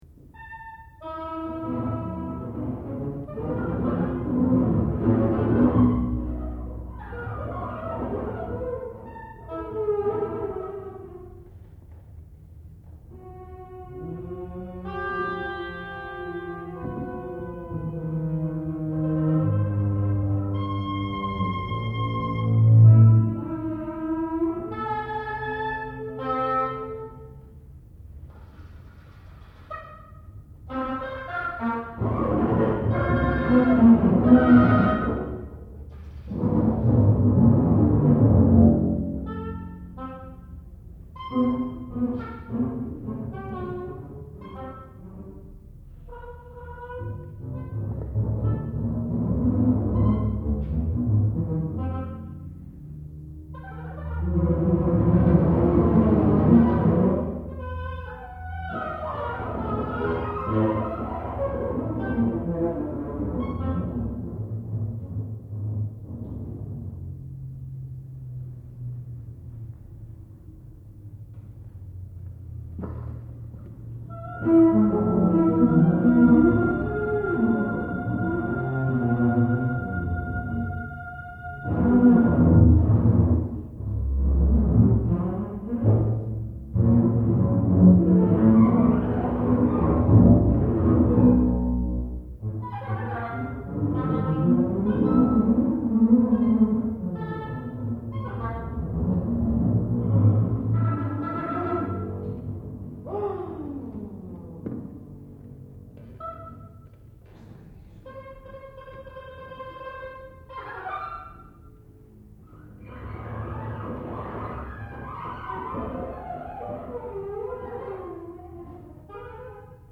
Klub Na Kotłowem, Krakow
Multiple 5 na obój i kontrabas / for oboe and double-bass